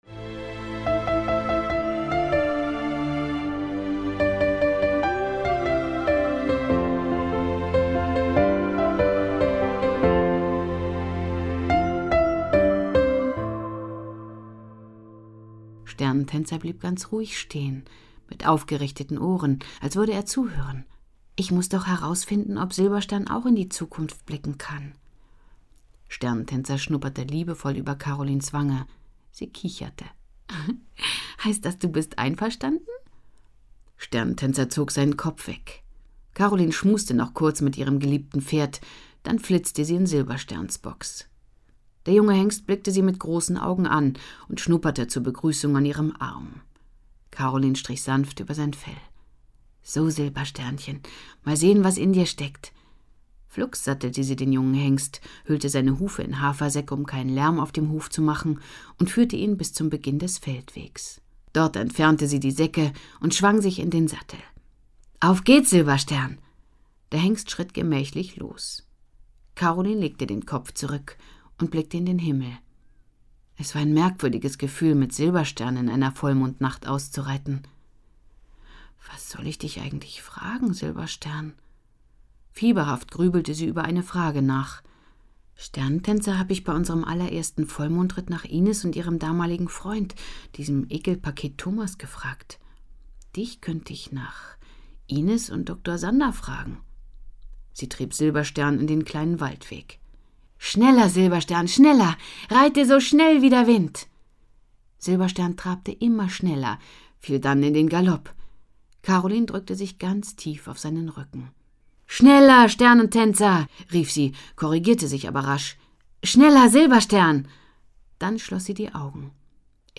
Hörbuch, 2 CDs, 158 Minuten